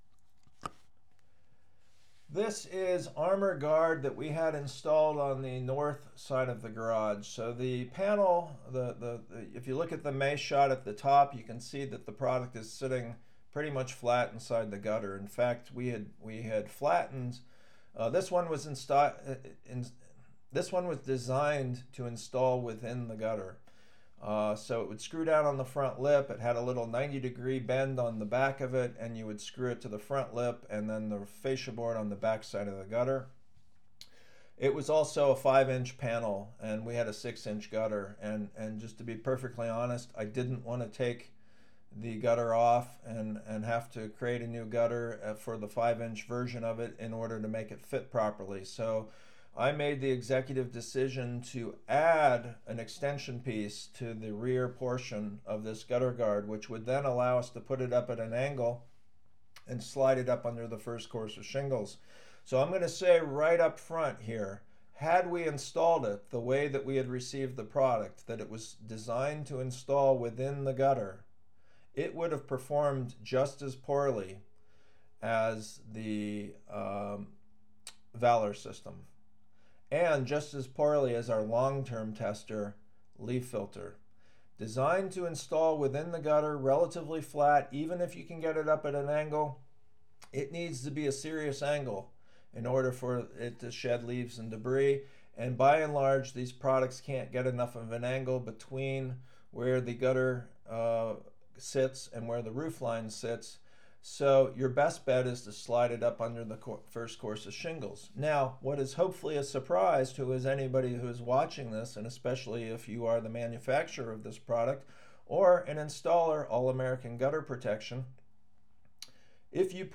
Commentator